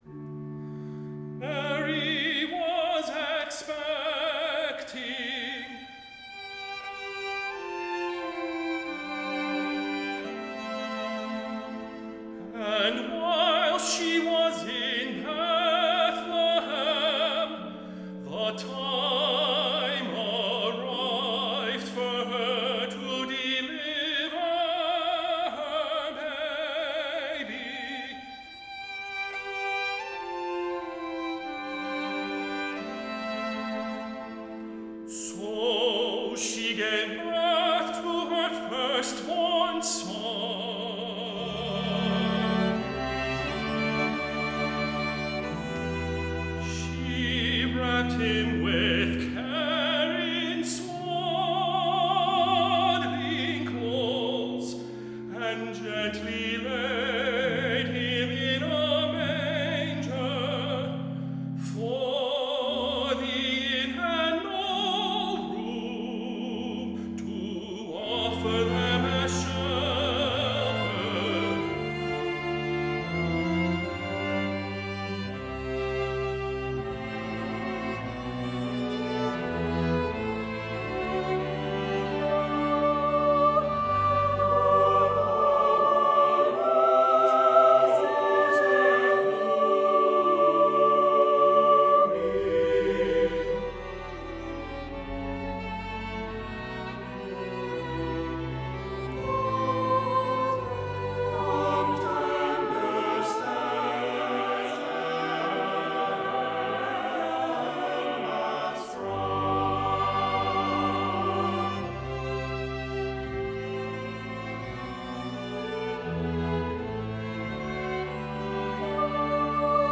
Voicing: "SATB","Solo"